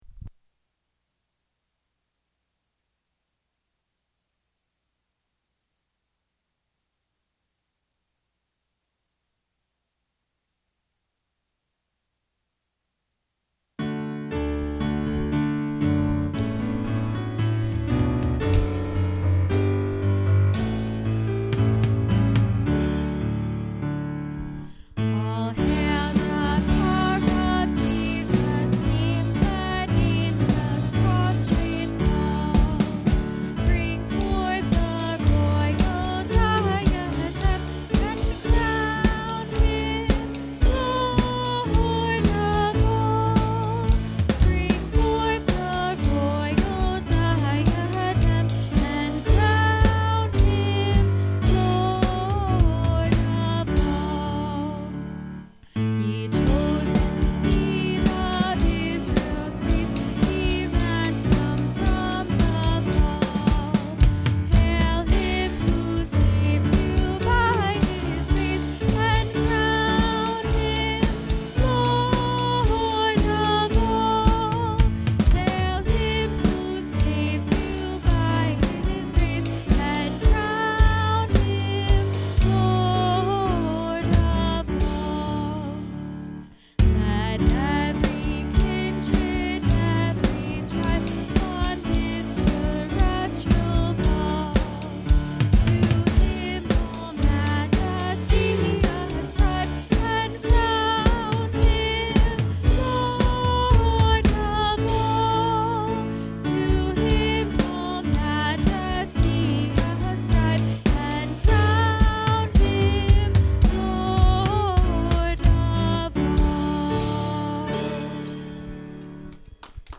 Acts 10 Service Type: Sunday Morning Worship Intro